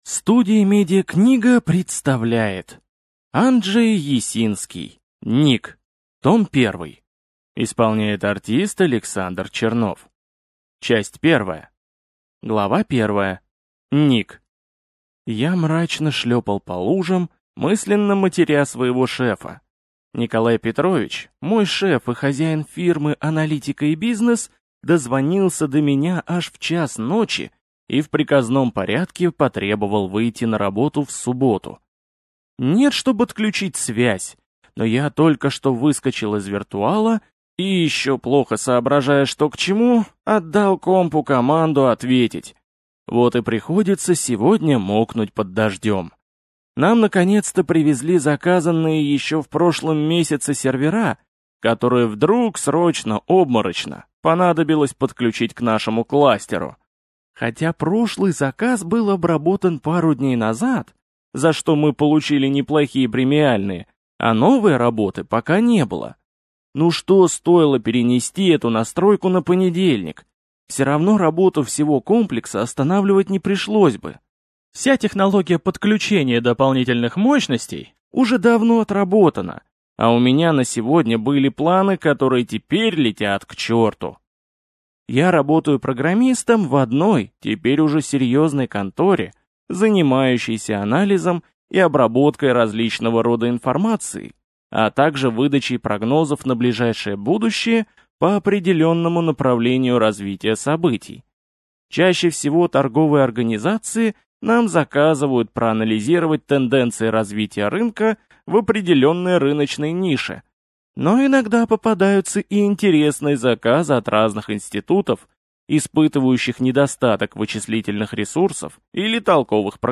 Аудиокнига Ник. Том 1 | Библиотека аудиокниг